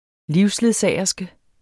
Udtale [ ˈliws- ]